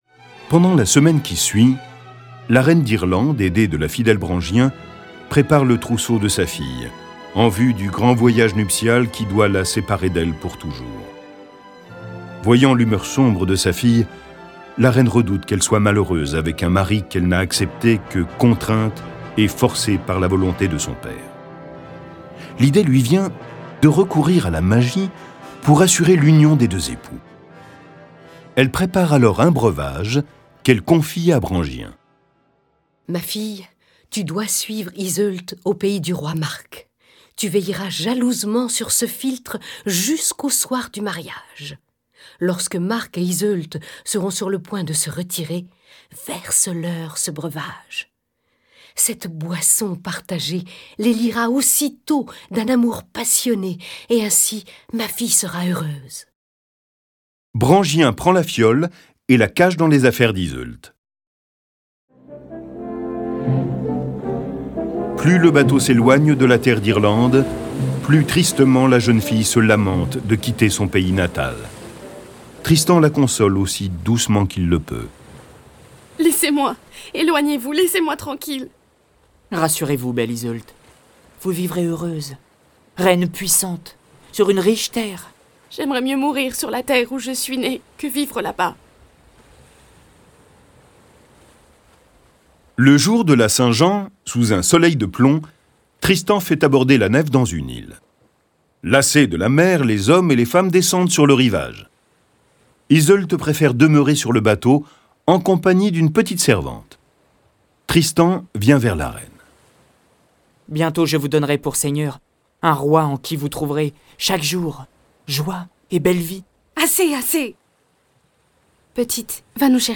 Le récit sonore de la plus célèbre des légendes médiévales est animé par plusieurs voix et accompagné de plus de trente morceaux de musique classique.
Le récit et les dialogues sont illustrés avec les musiques de Bach, Borodine, Charpentier, Corelli, Debussy, Delibes, Dvorak, Grieg, Mendelssohn, Mozart, Pergolèse, Schumann, Smetana, Tchaïkovski, Telemann, Vivaldi, Wagner.